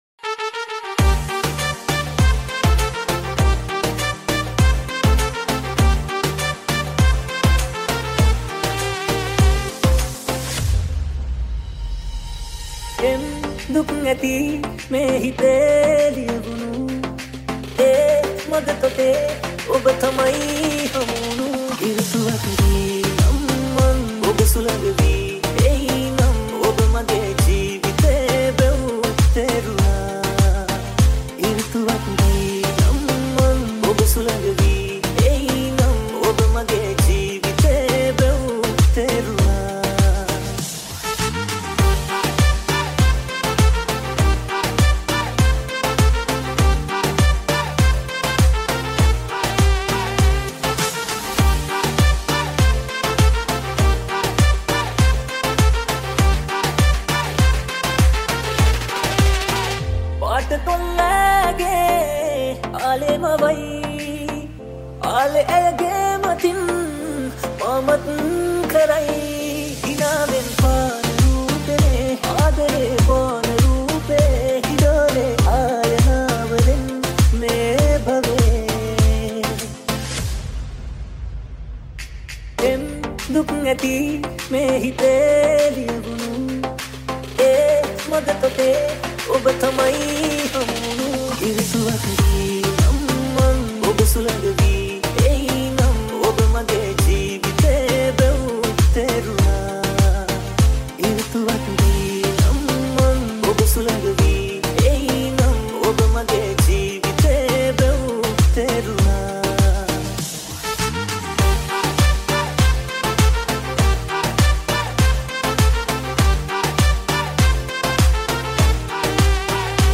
2021 Sinhala Dj Remix